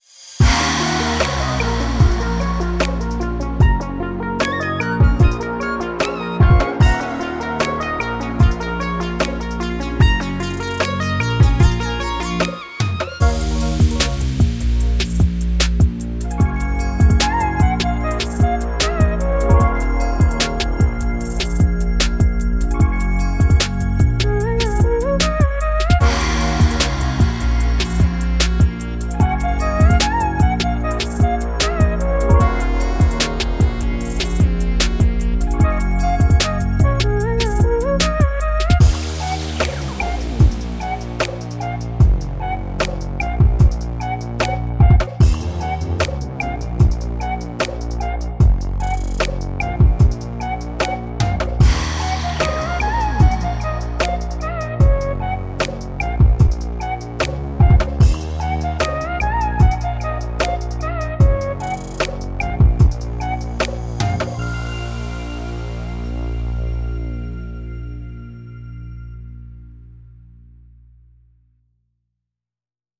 tema dizi müziği, rahatlatıcı eğlenceli enerjik fon müziği.